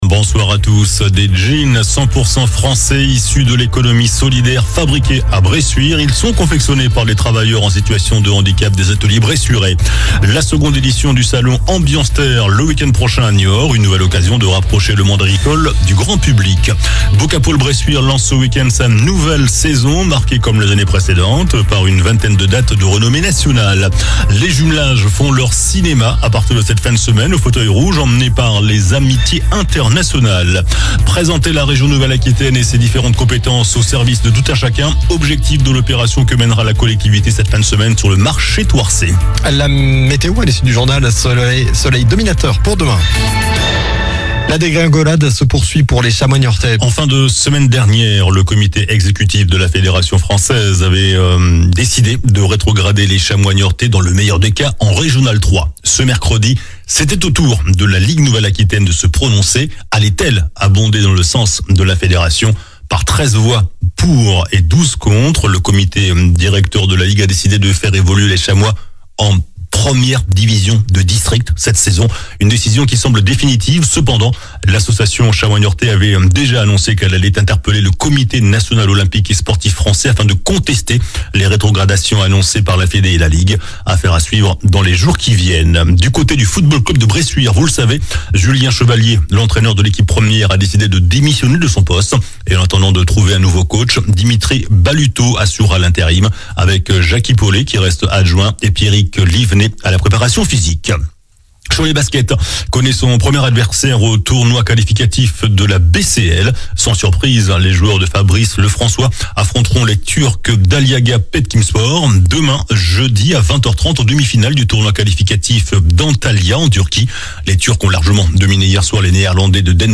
JOURNAL DU MERCREDI 18 SEPTEMBRE ( SOIR )